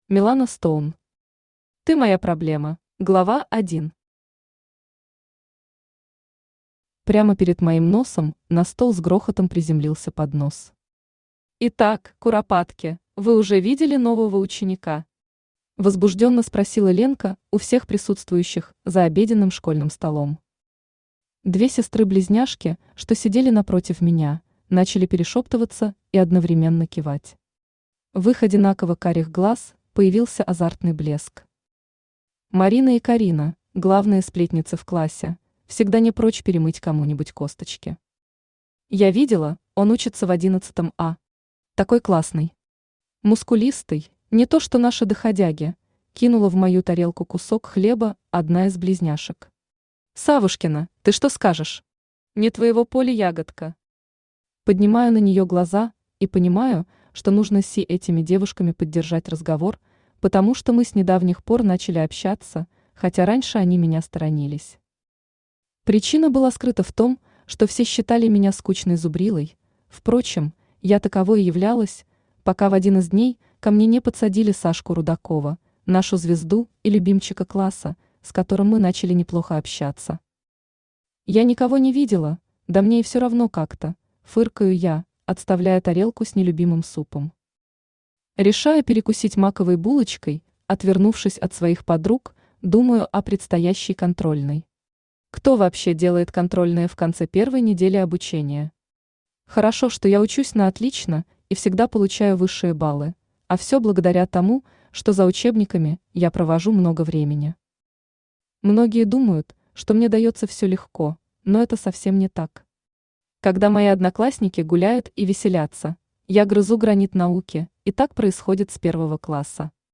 Аудиокнига Ты моя проблема | Библиотека аудиокниг
Aудиокнига Ты моя проблема Автор Милана Стоун Читает аудиокнигу Авточтец ЛитРес.